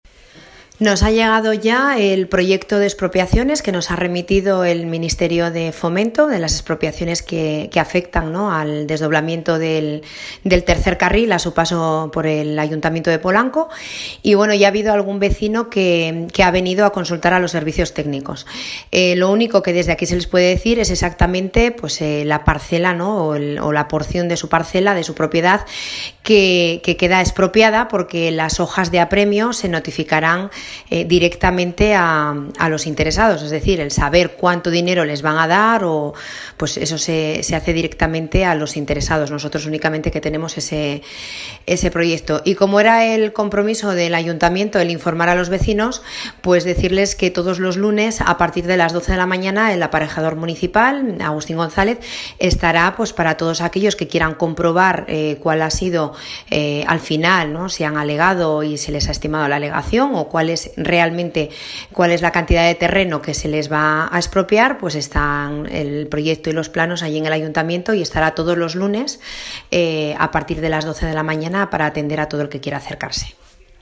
Alcaldesa-Polanco-expropiaciones-autovia.mp3